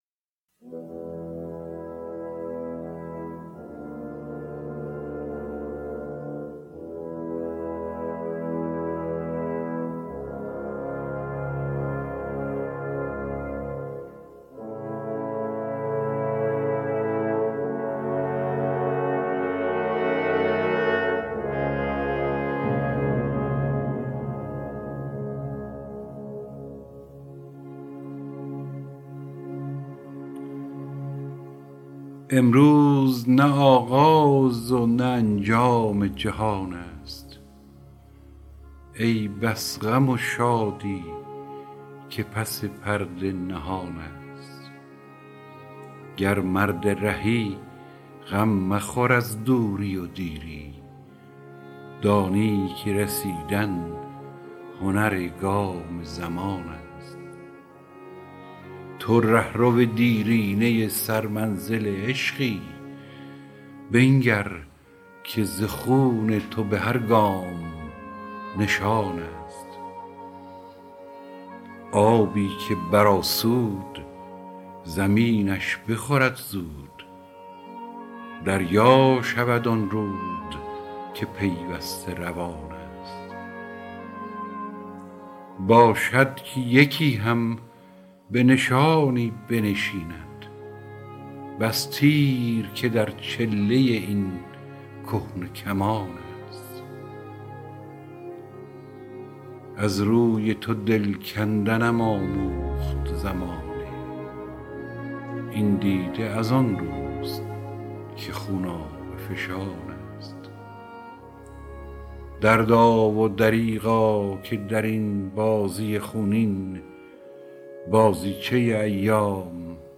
دانلود دکلمه هنرگام زمان با صدای هوشنگ ابتهاج با متن دکلمه
گوینده :   [هوشنگ ابتهاج]